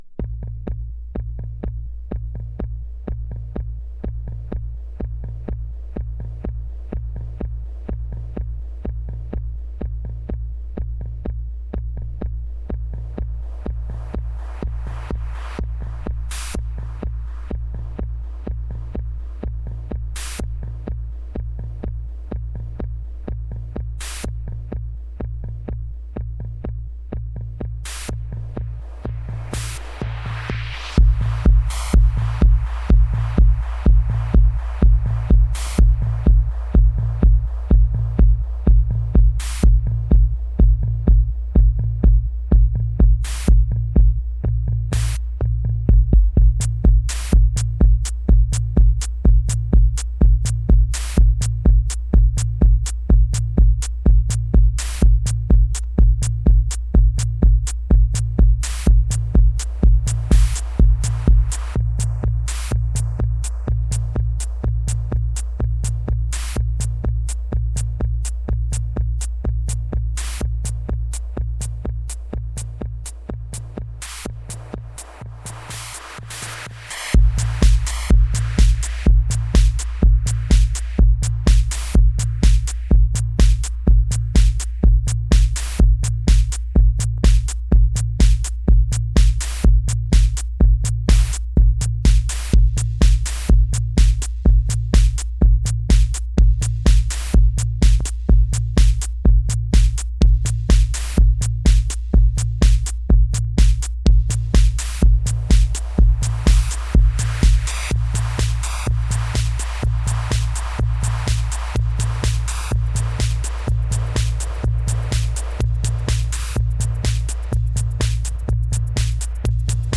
( 19M 2F - COMPLETE - Techno 2008 )